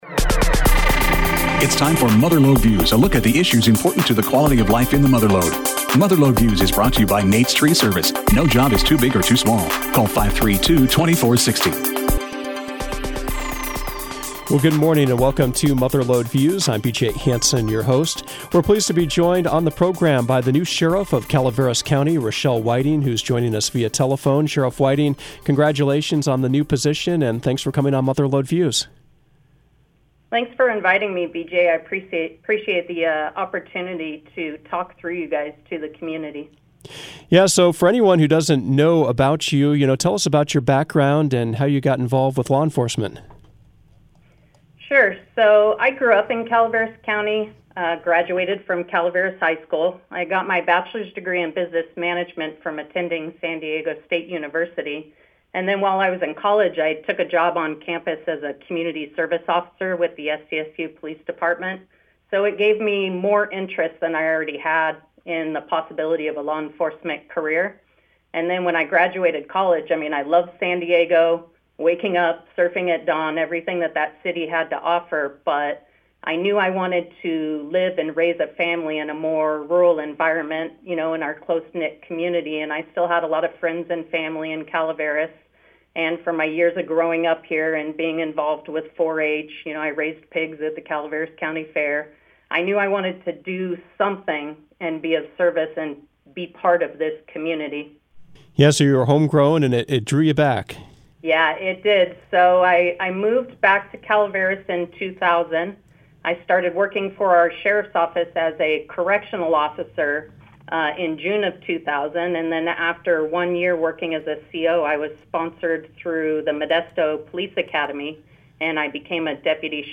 Mother Lode Views featured a conversation with new Calaveras Sheriff Rachelle Whiting about her goals and priorities for the department. Other topics included the impacts of state legislation, utilizing artificial intelligence in law enforcement, cold case investigations, and the rise in fentanyl abuse.